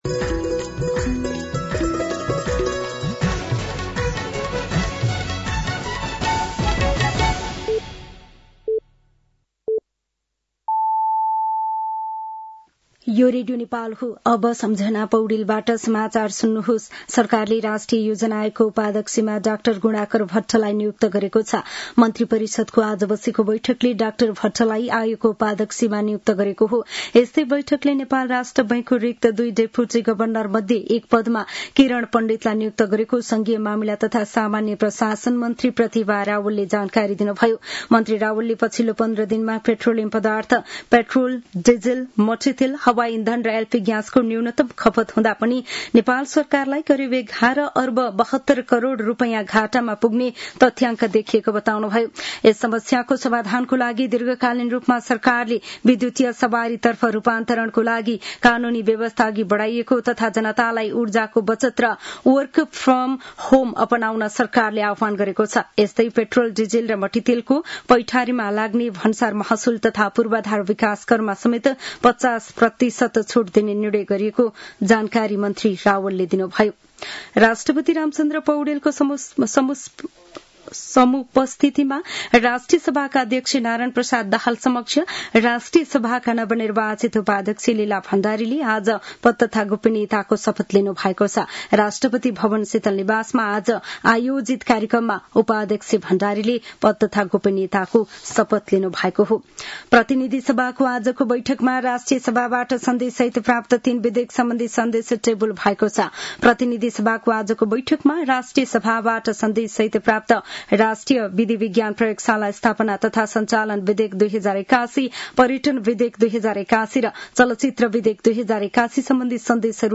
साँझ ५ बजेको नेपाली समाचार : २४ चैत , २०८२